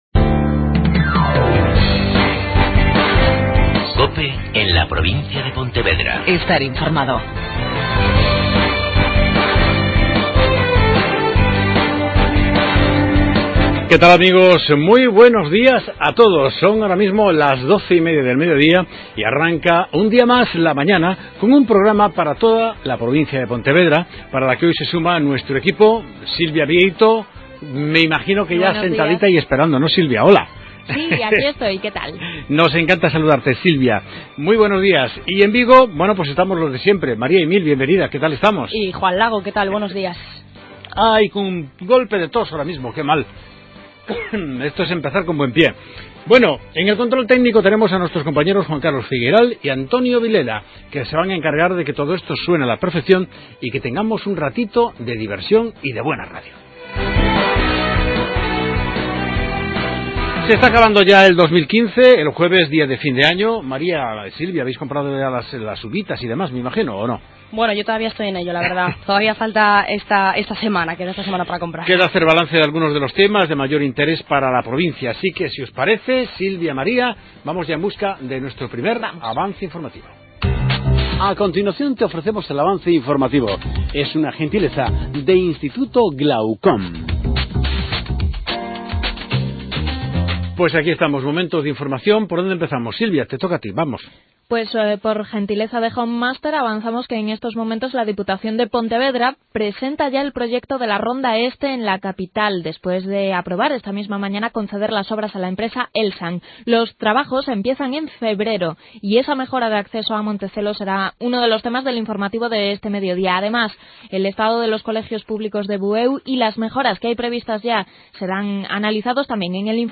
AUDIO: Entrevista a Ignacio López-Chaves, Delegado Territorial en Vigo de la Xunta de Galicia.